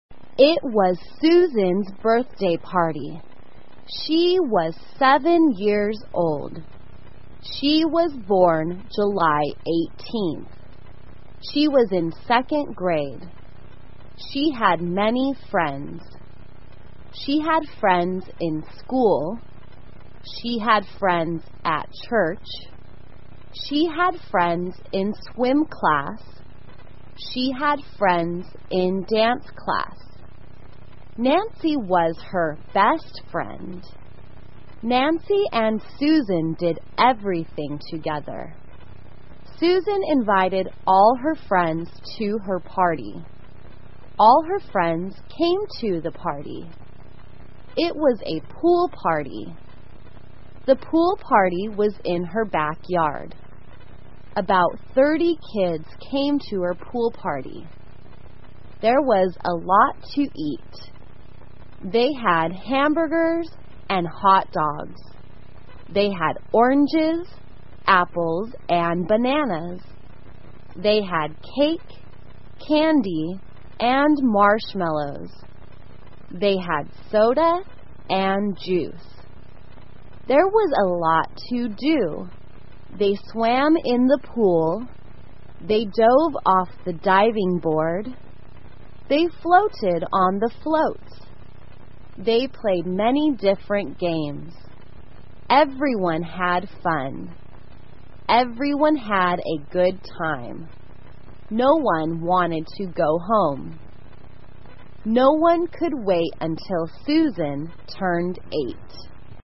慢速英语短文听力 生日派对 听力文件下载—在线英语听力室